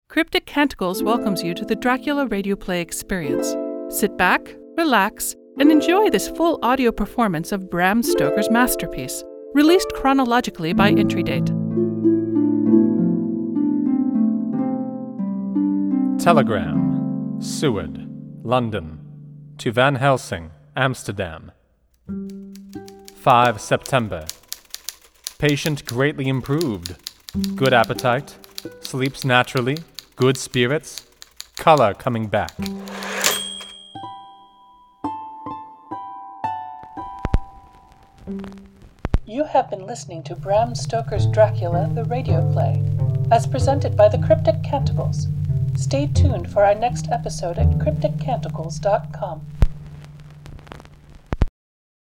SFX and Music